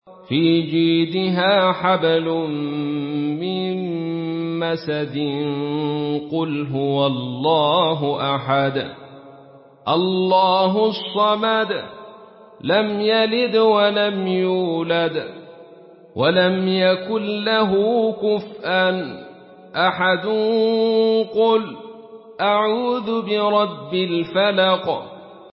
Surah আল-ইখলাস MP3 in the Voice of Abdul Rashid Sufi in Khalaf Narration
Surah আল-ইখলাস MP3 by Abdul Rashid Sufi in Khalaf An Hamza narration.
Murattal Khalaf An Hamza